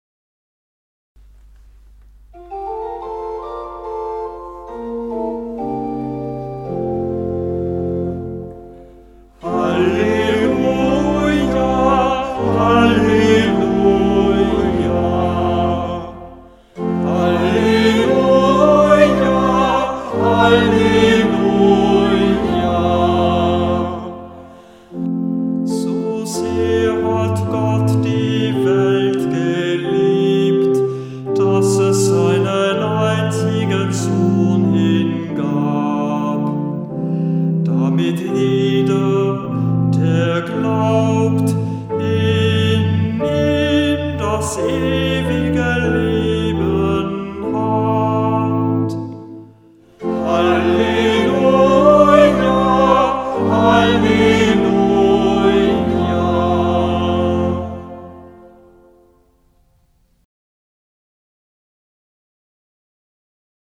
Ruf vor dem Evangelium - November 2025
Hörbeispiele aus dem Halleluja-Büchlein
Kantor wenn nicht anders angegeben